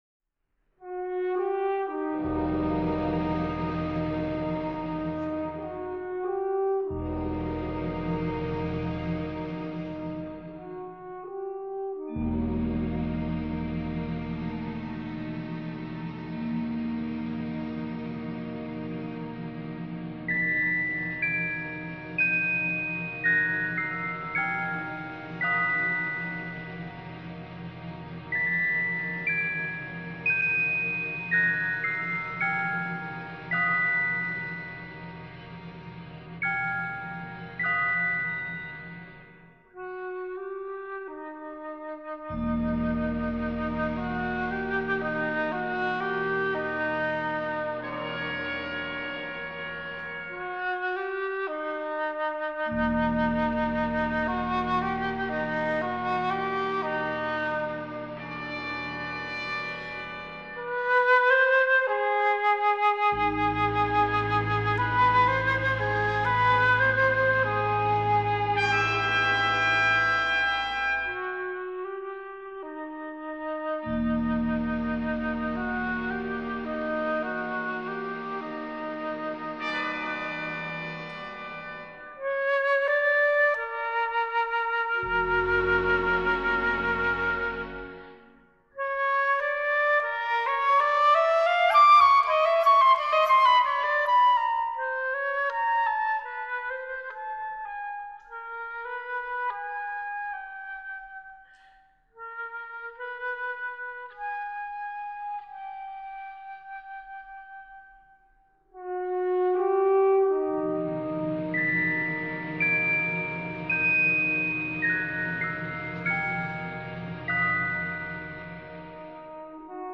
флейта